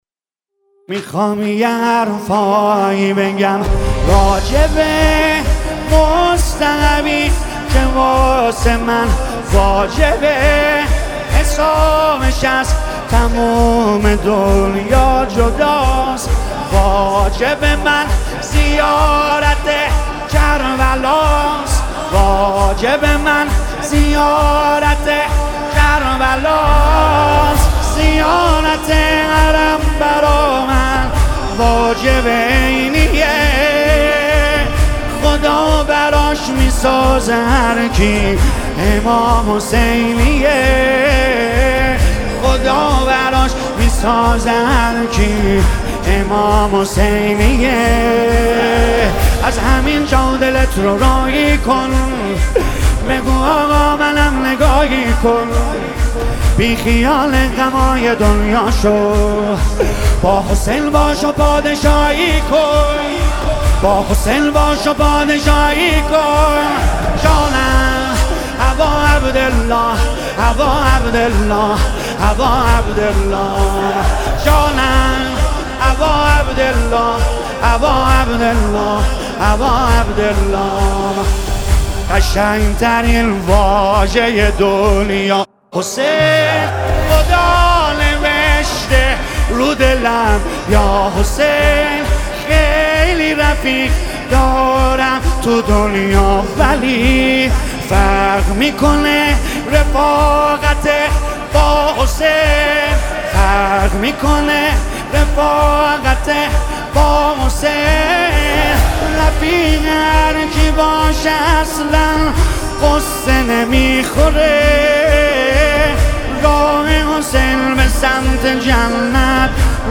مداحی ماه محرم